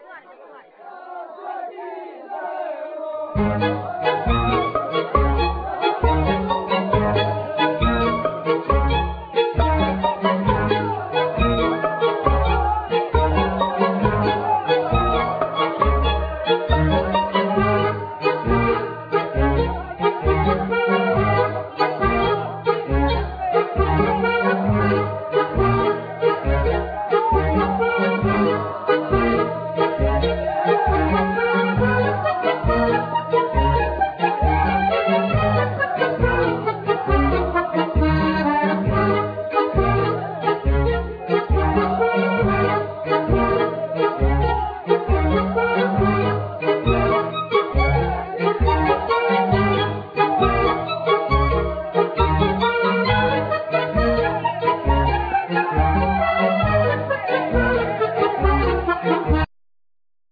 Accordion,Clarinet,Piano,Percussion,Computer
Violin
Doudouk
Voice
Viola
Cello
Double Bass